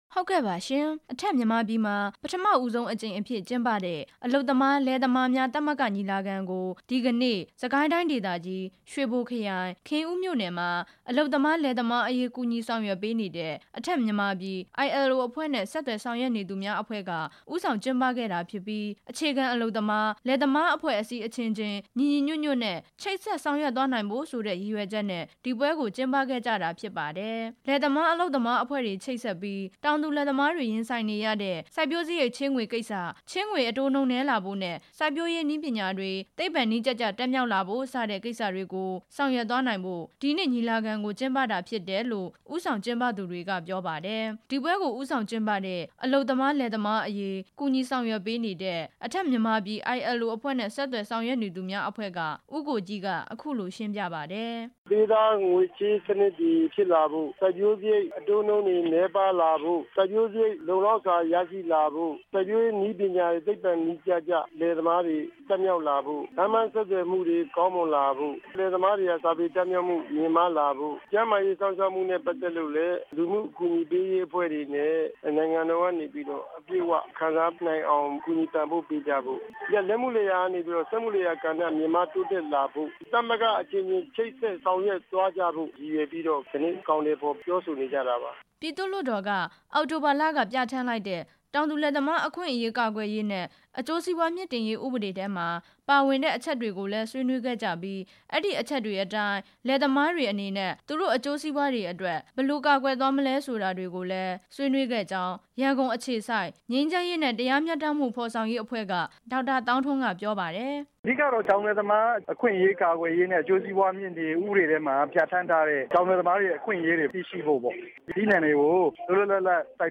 ညီလာခံအကြောင်း တင်ပြချက်